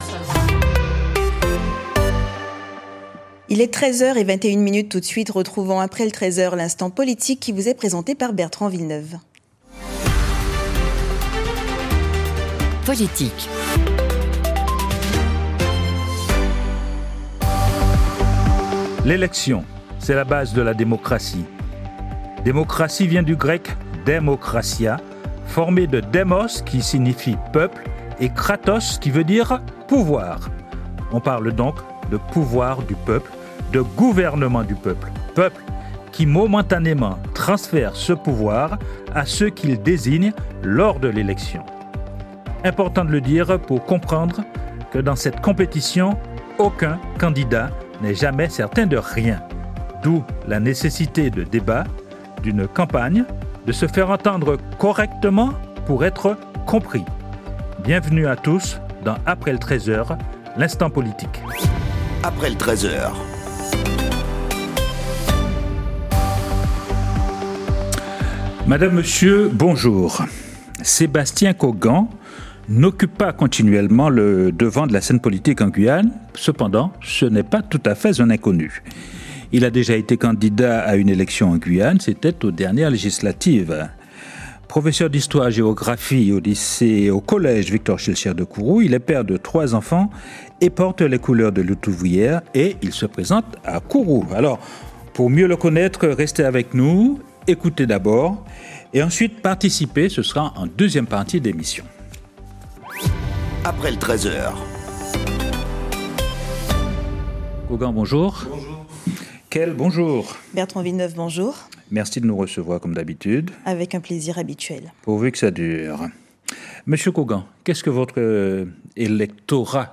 Guyane la 1ère - Radio